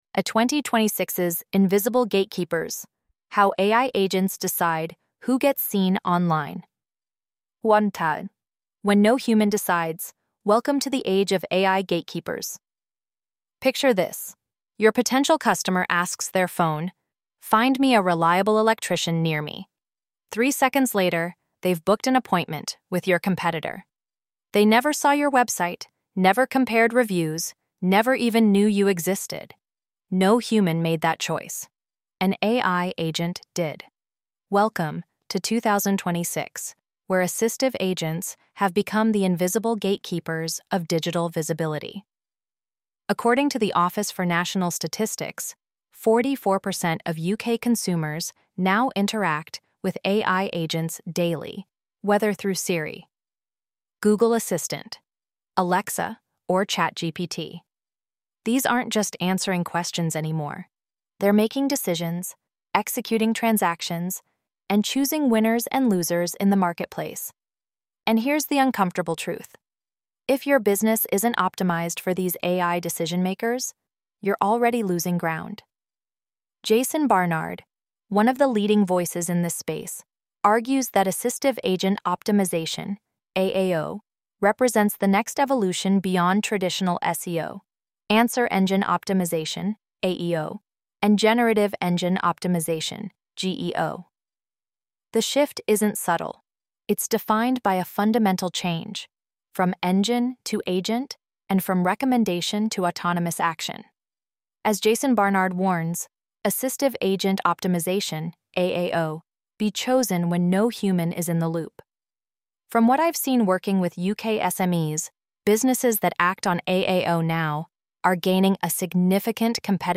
Audio narration of the article: 2026's Invisible Gatekeepers: How AI Agents Decide Who Gets Seen Online